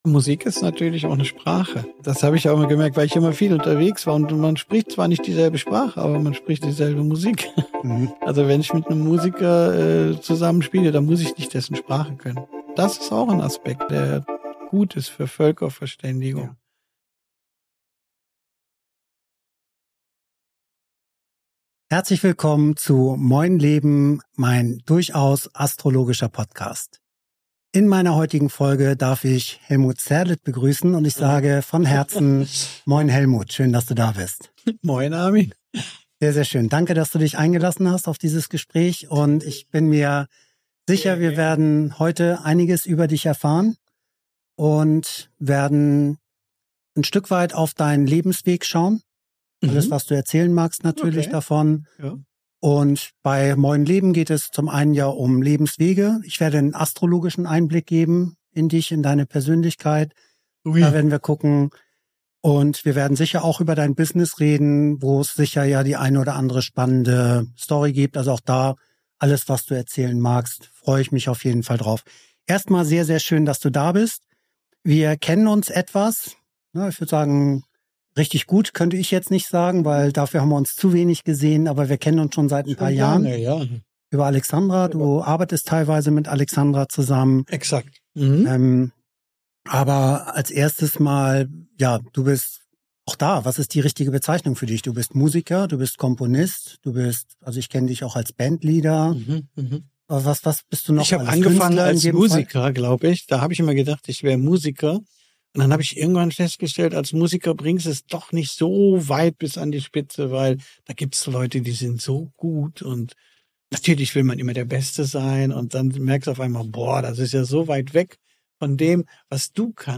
„Musik ist Liebe“ Helmut Zerlett im Gespräch ~ Moin Leben - Lebenswege, Astrologie, Business Podcast